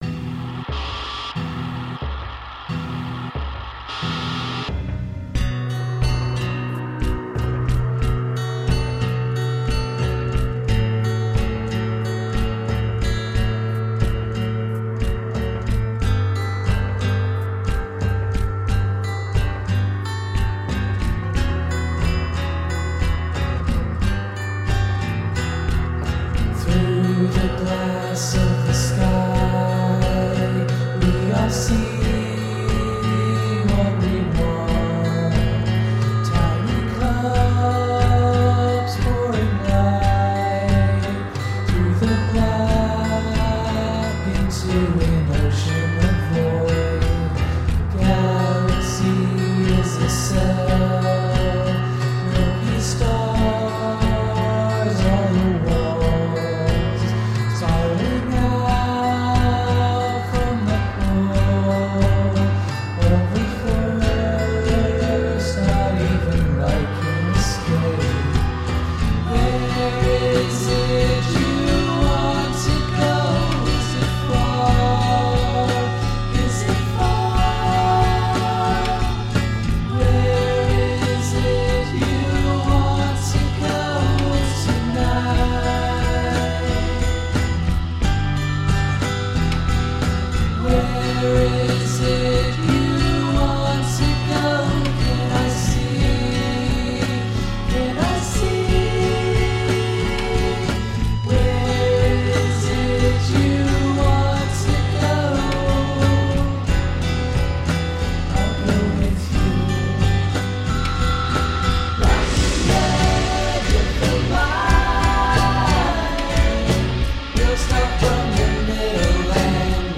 Lo-fi pop at its best.
Tagged as: Alt Rock, Other, Indie Rock